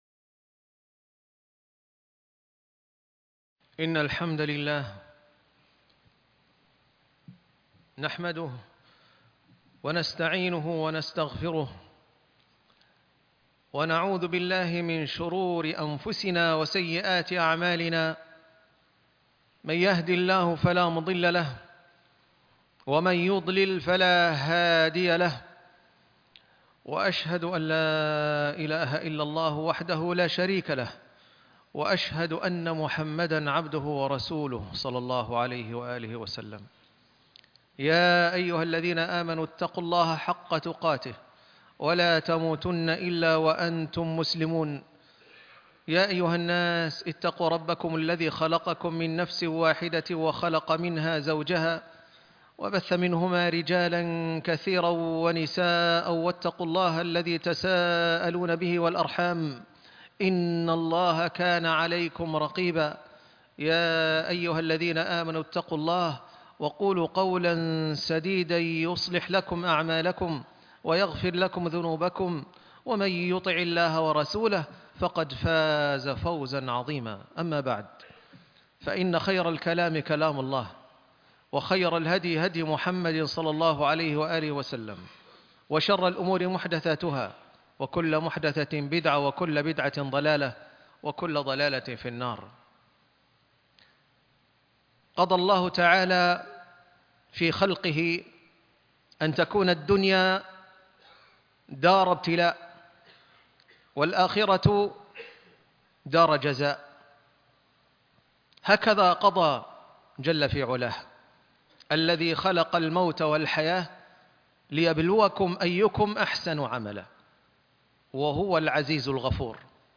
الصمود أمام الفتن ج1 ( خطب الجمعة